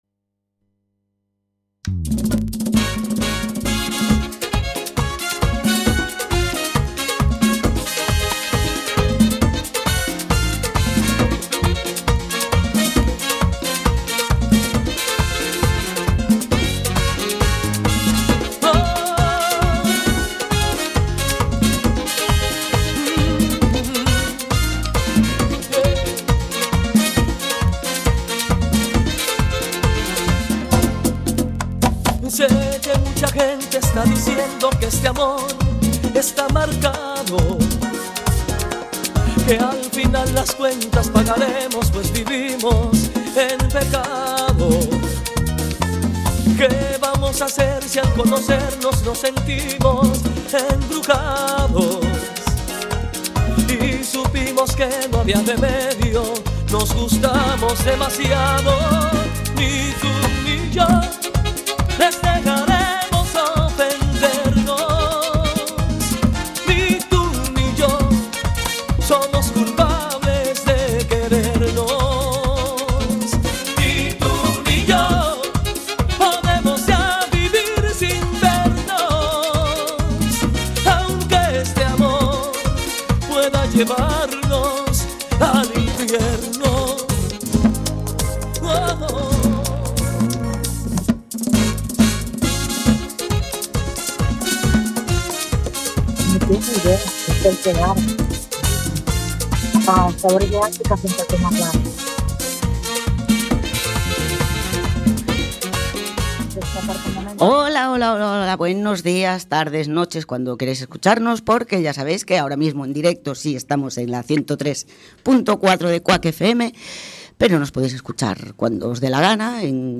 Somos un par de malhumorhadas que, en cada programa, creceremos en número y en espíritu, acompañándonos de personas que, como nosotras, se pre-ocupan y se ocupan de las cosas importantes, dando apoyo a proyectos y movimientos sociales con diferentes causas y objetivos. Todo esto con mucho humor (bueno y malo) y con mucha música y diversión, todos los miércoles a las 20:00 en Cuac FM (redifusión: sábados 10:00) y los jueves a las 14:00 en OMC Radio.